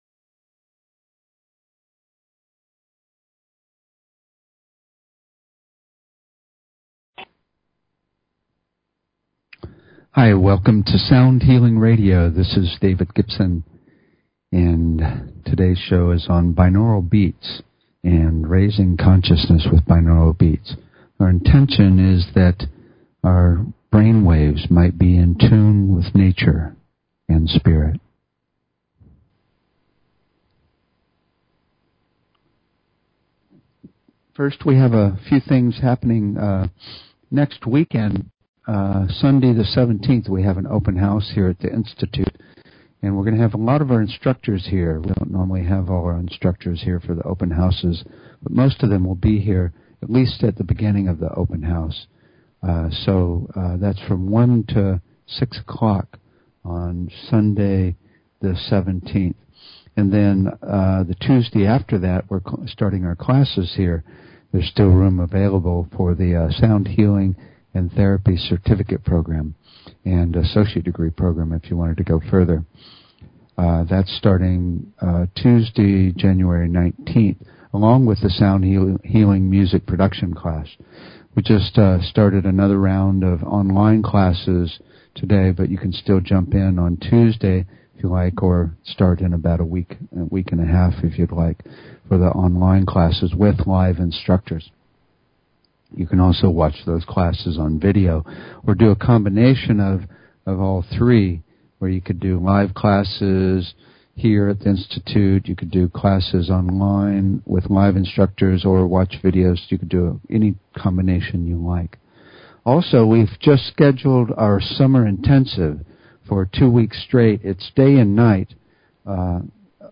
Talk Show Episode, Audio Podcast, Sound_Healing and Courtesy of BBS Radio on , show guests , about , categorized as
We will play some examples to demonstrate Alpha, Theta and Delta entrainment. We'll also discuss the Schumann Resonance -- a frequency ever present in the atmosphere that entrains our brain continuously.
The show is a sound combination of discussion and experience including the following topics: Toning, Chanting and Overtone Singing - Root Frequency Entrainment - Sound to Improve Learning -Disabilities - Using Sound to Connect to Spirit - Tuning Fork Treatments - Voice Analysis Technologies - Chakra Balancing - Sound to Induce Desired S